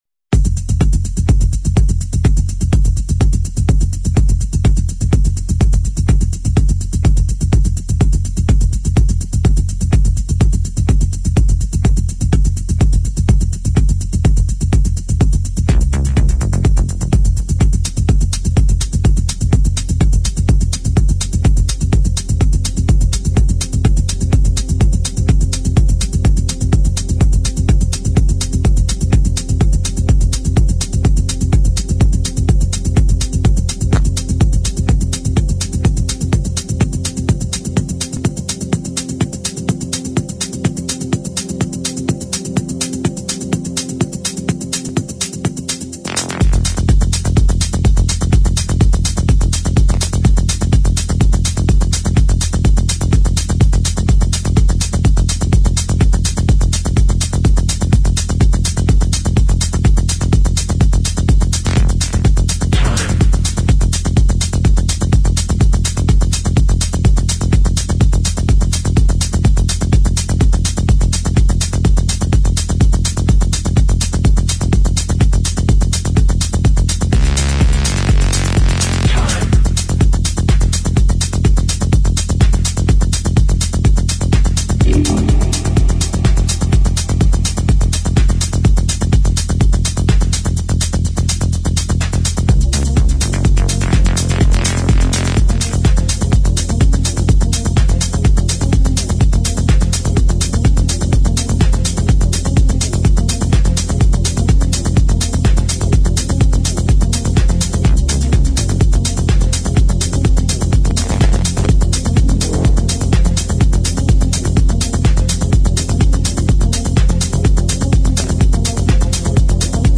[ TECHNO | ACID ]